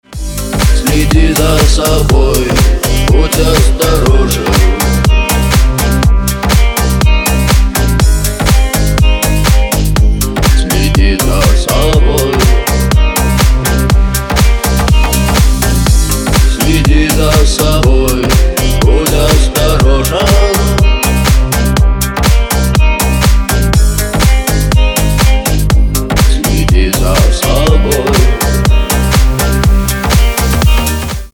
мужской голос
retromix
Club House
басы
ремиксы